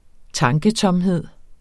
Udtale [ -tʌmˌheðˀ ]